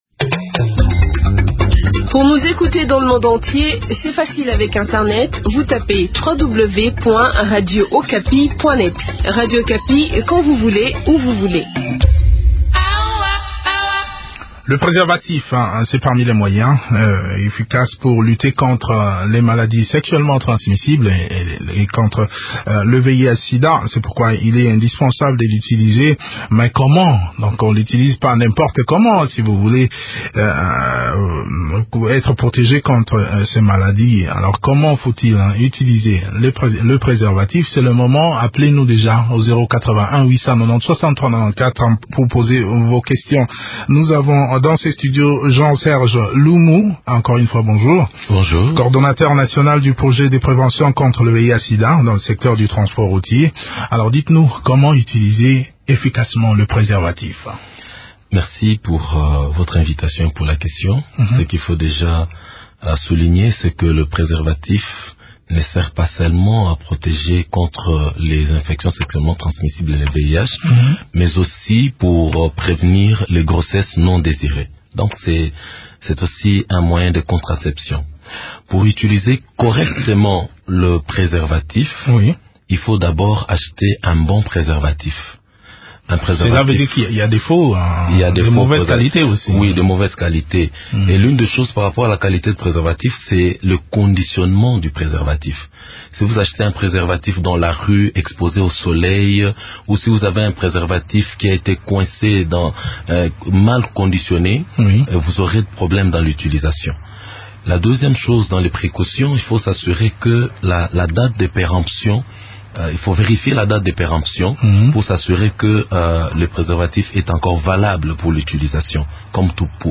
Explications dans cet entretien